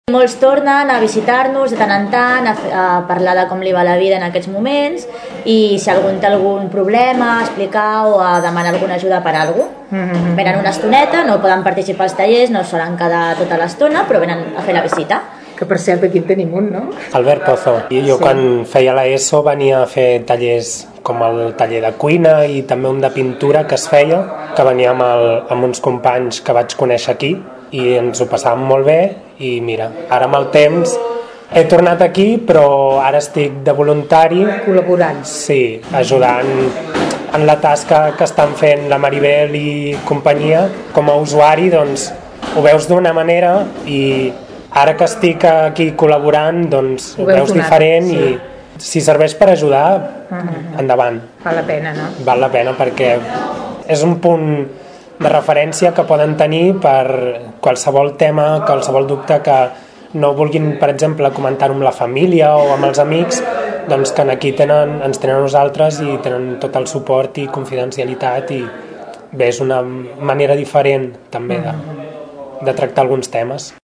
Què passa quan aquests joves són grans i ja no poden participar directament de la programació del centre? Escoltem l’experiència d’un d’ells.